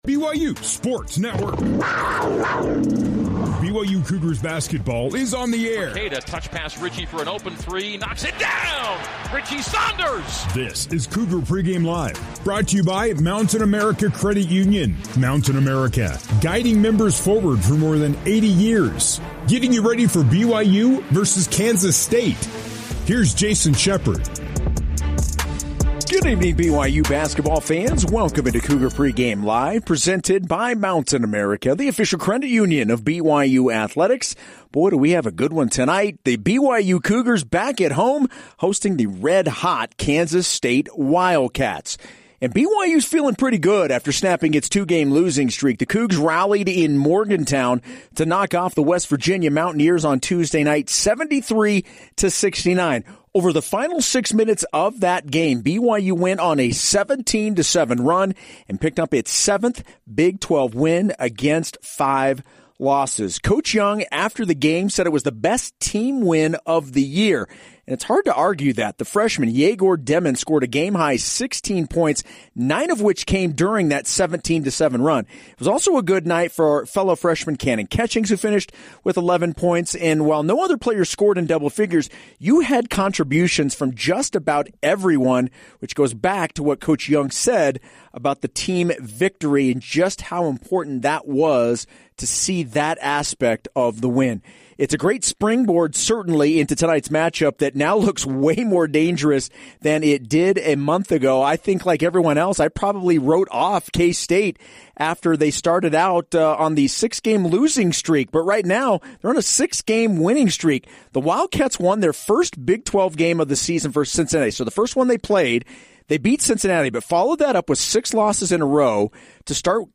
Listen to the broadcast of BYU men's basketball games, including play-by-play, pregame, postgame and highlights from the radio call